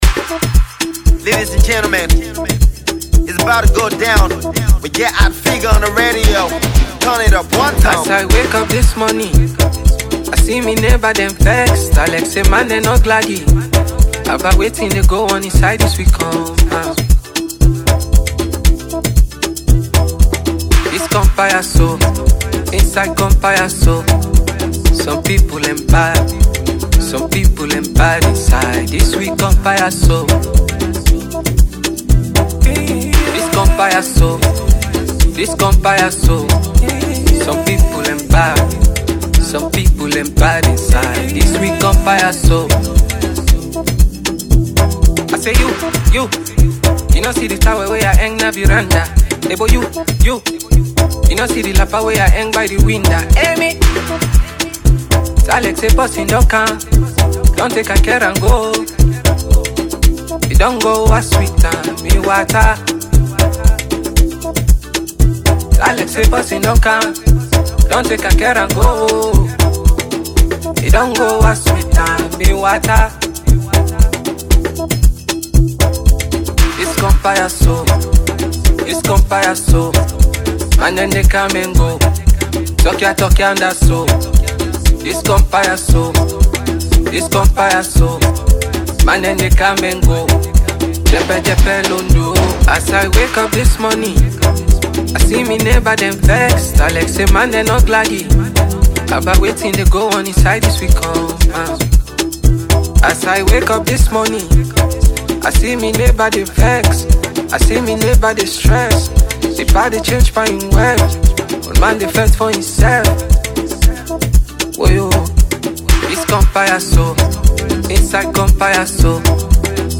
amapiano sound